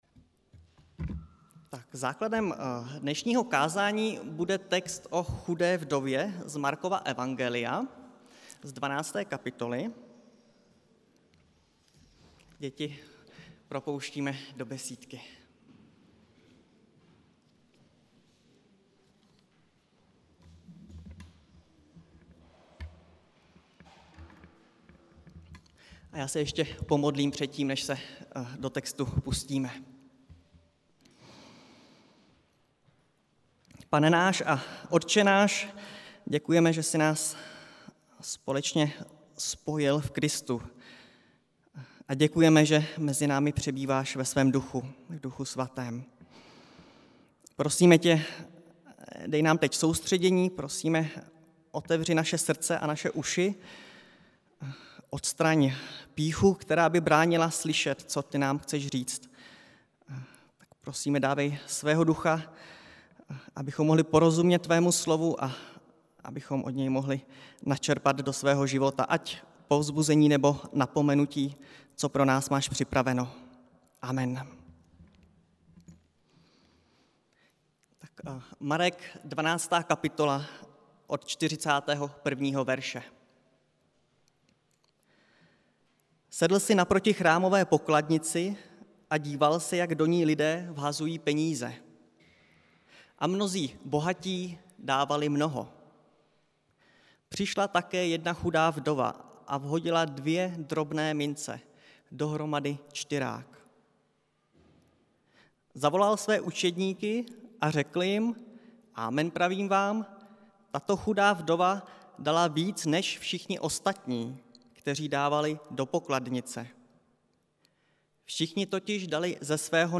Nedělní kázání 4.10.2020